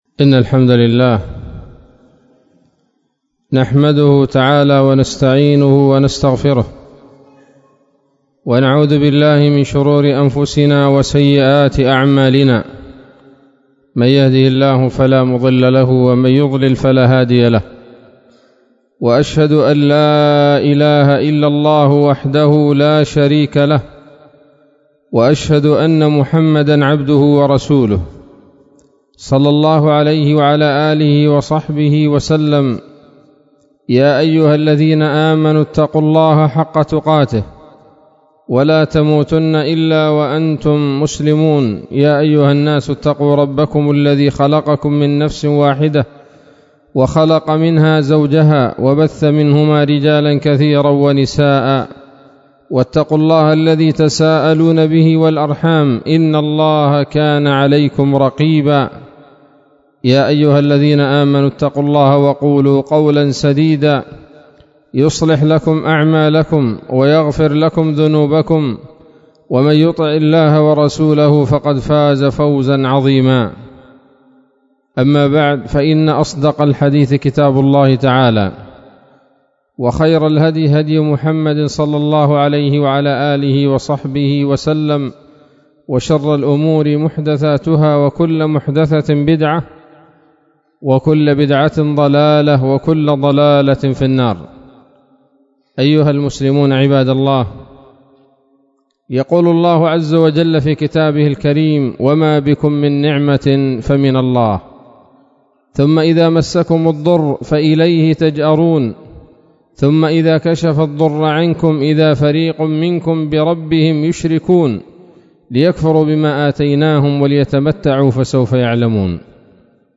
خطبة جمعة بعنوان: (( الهاتف الجوال آداب وأحكام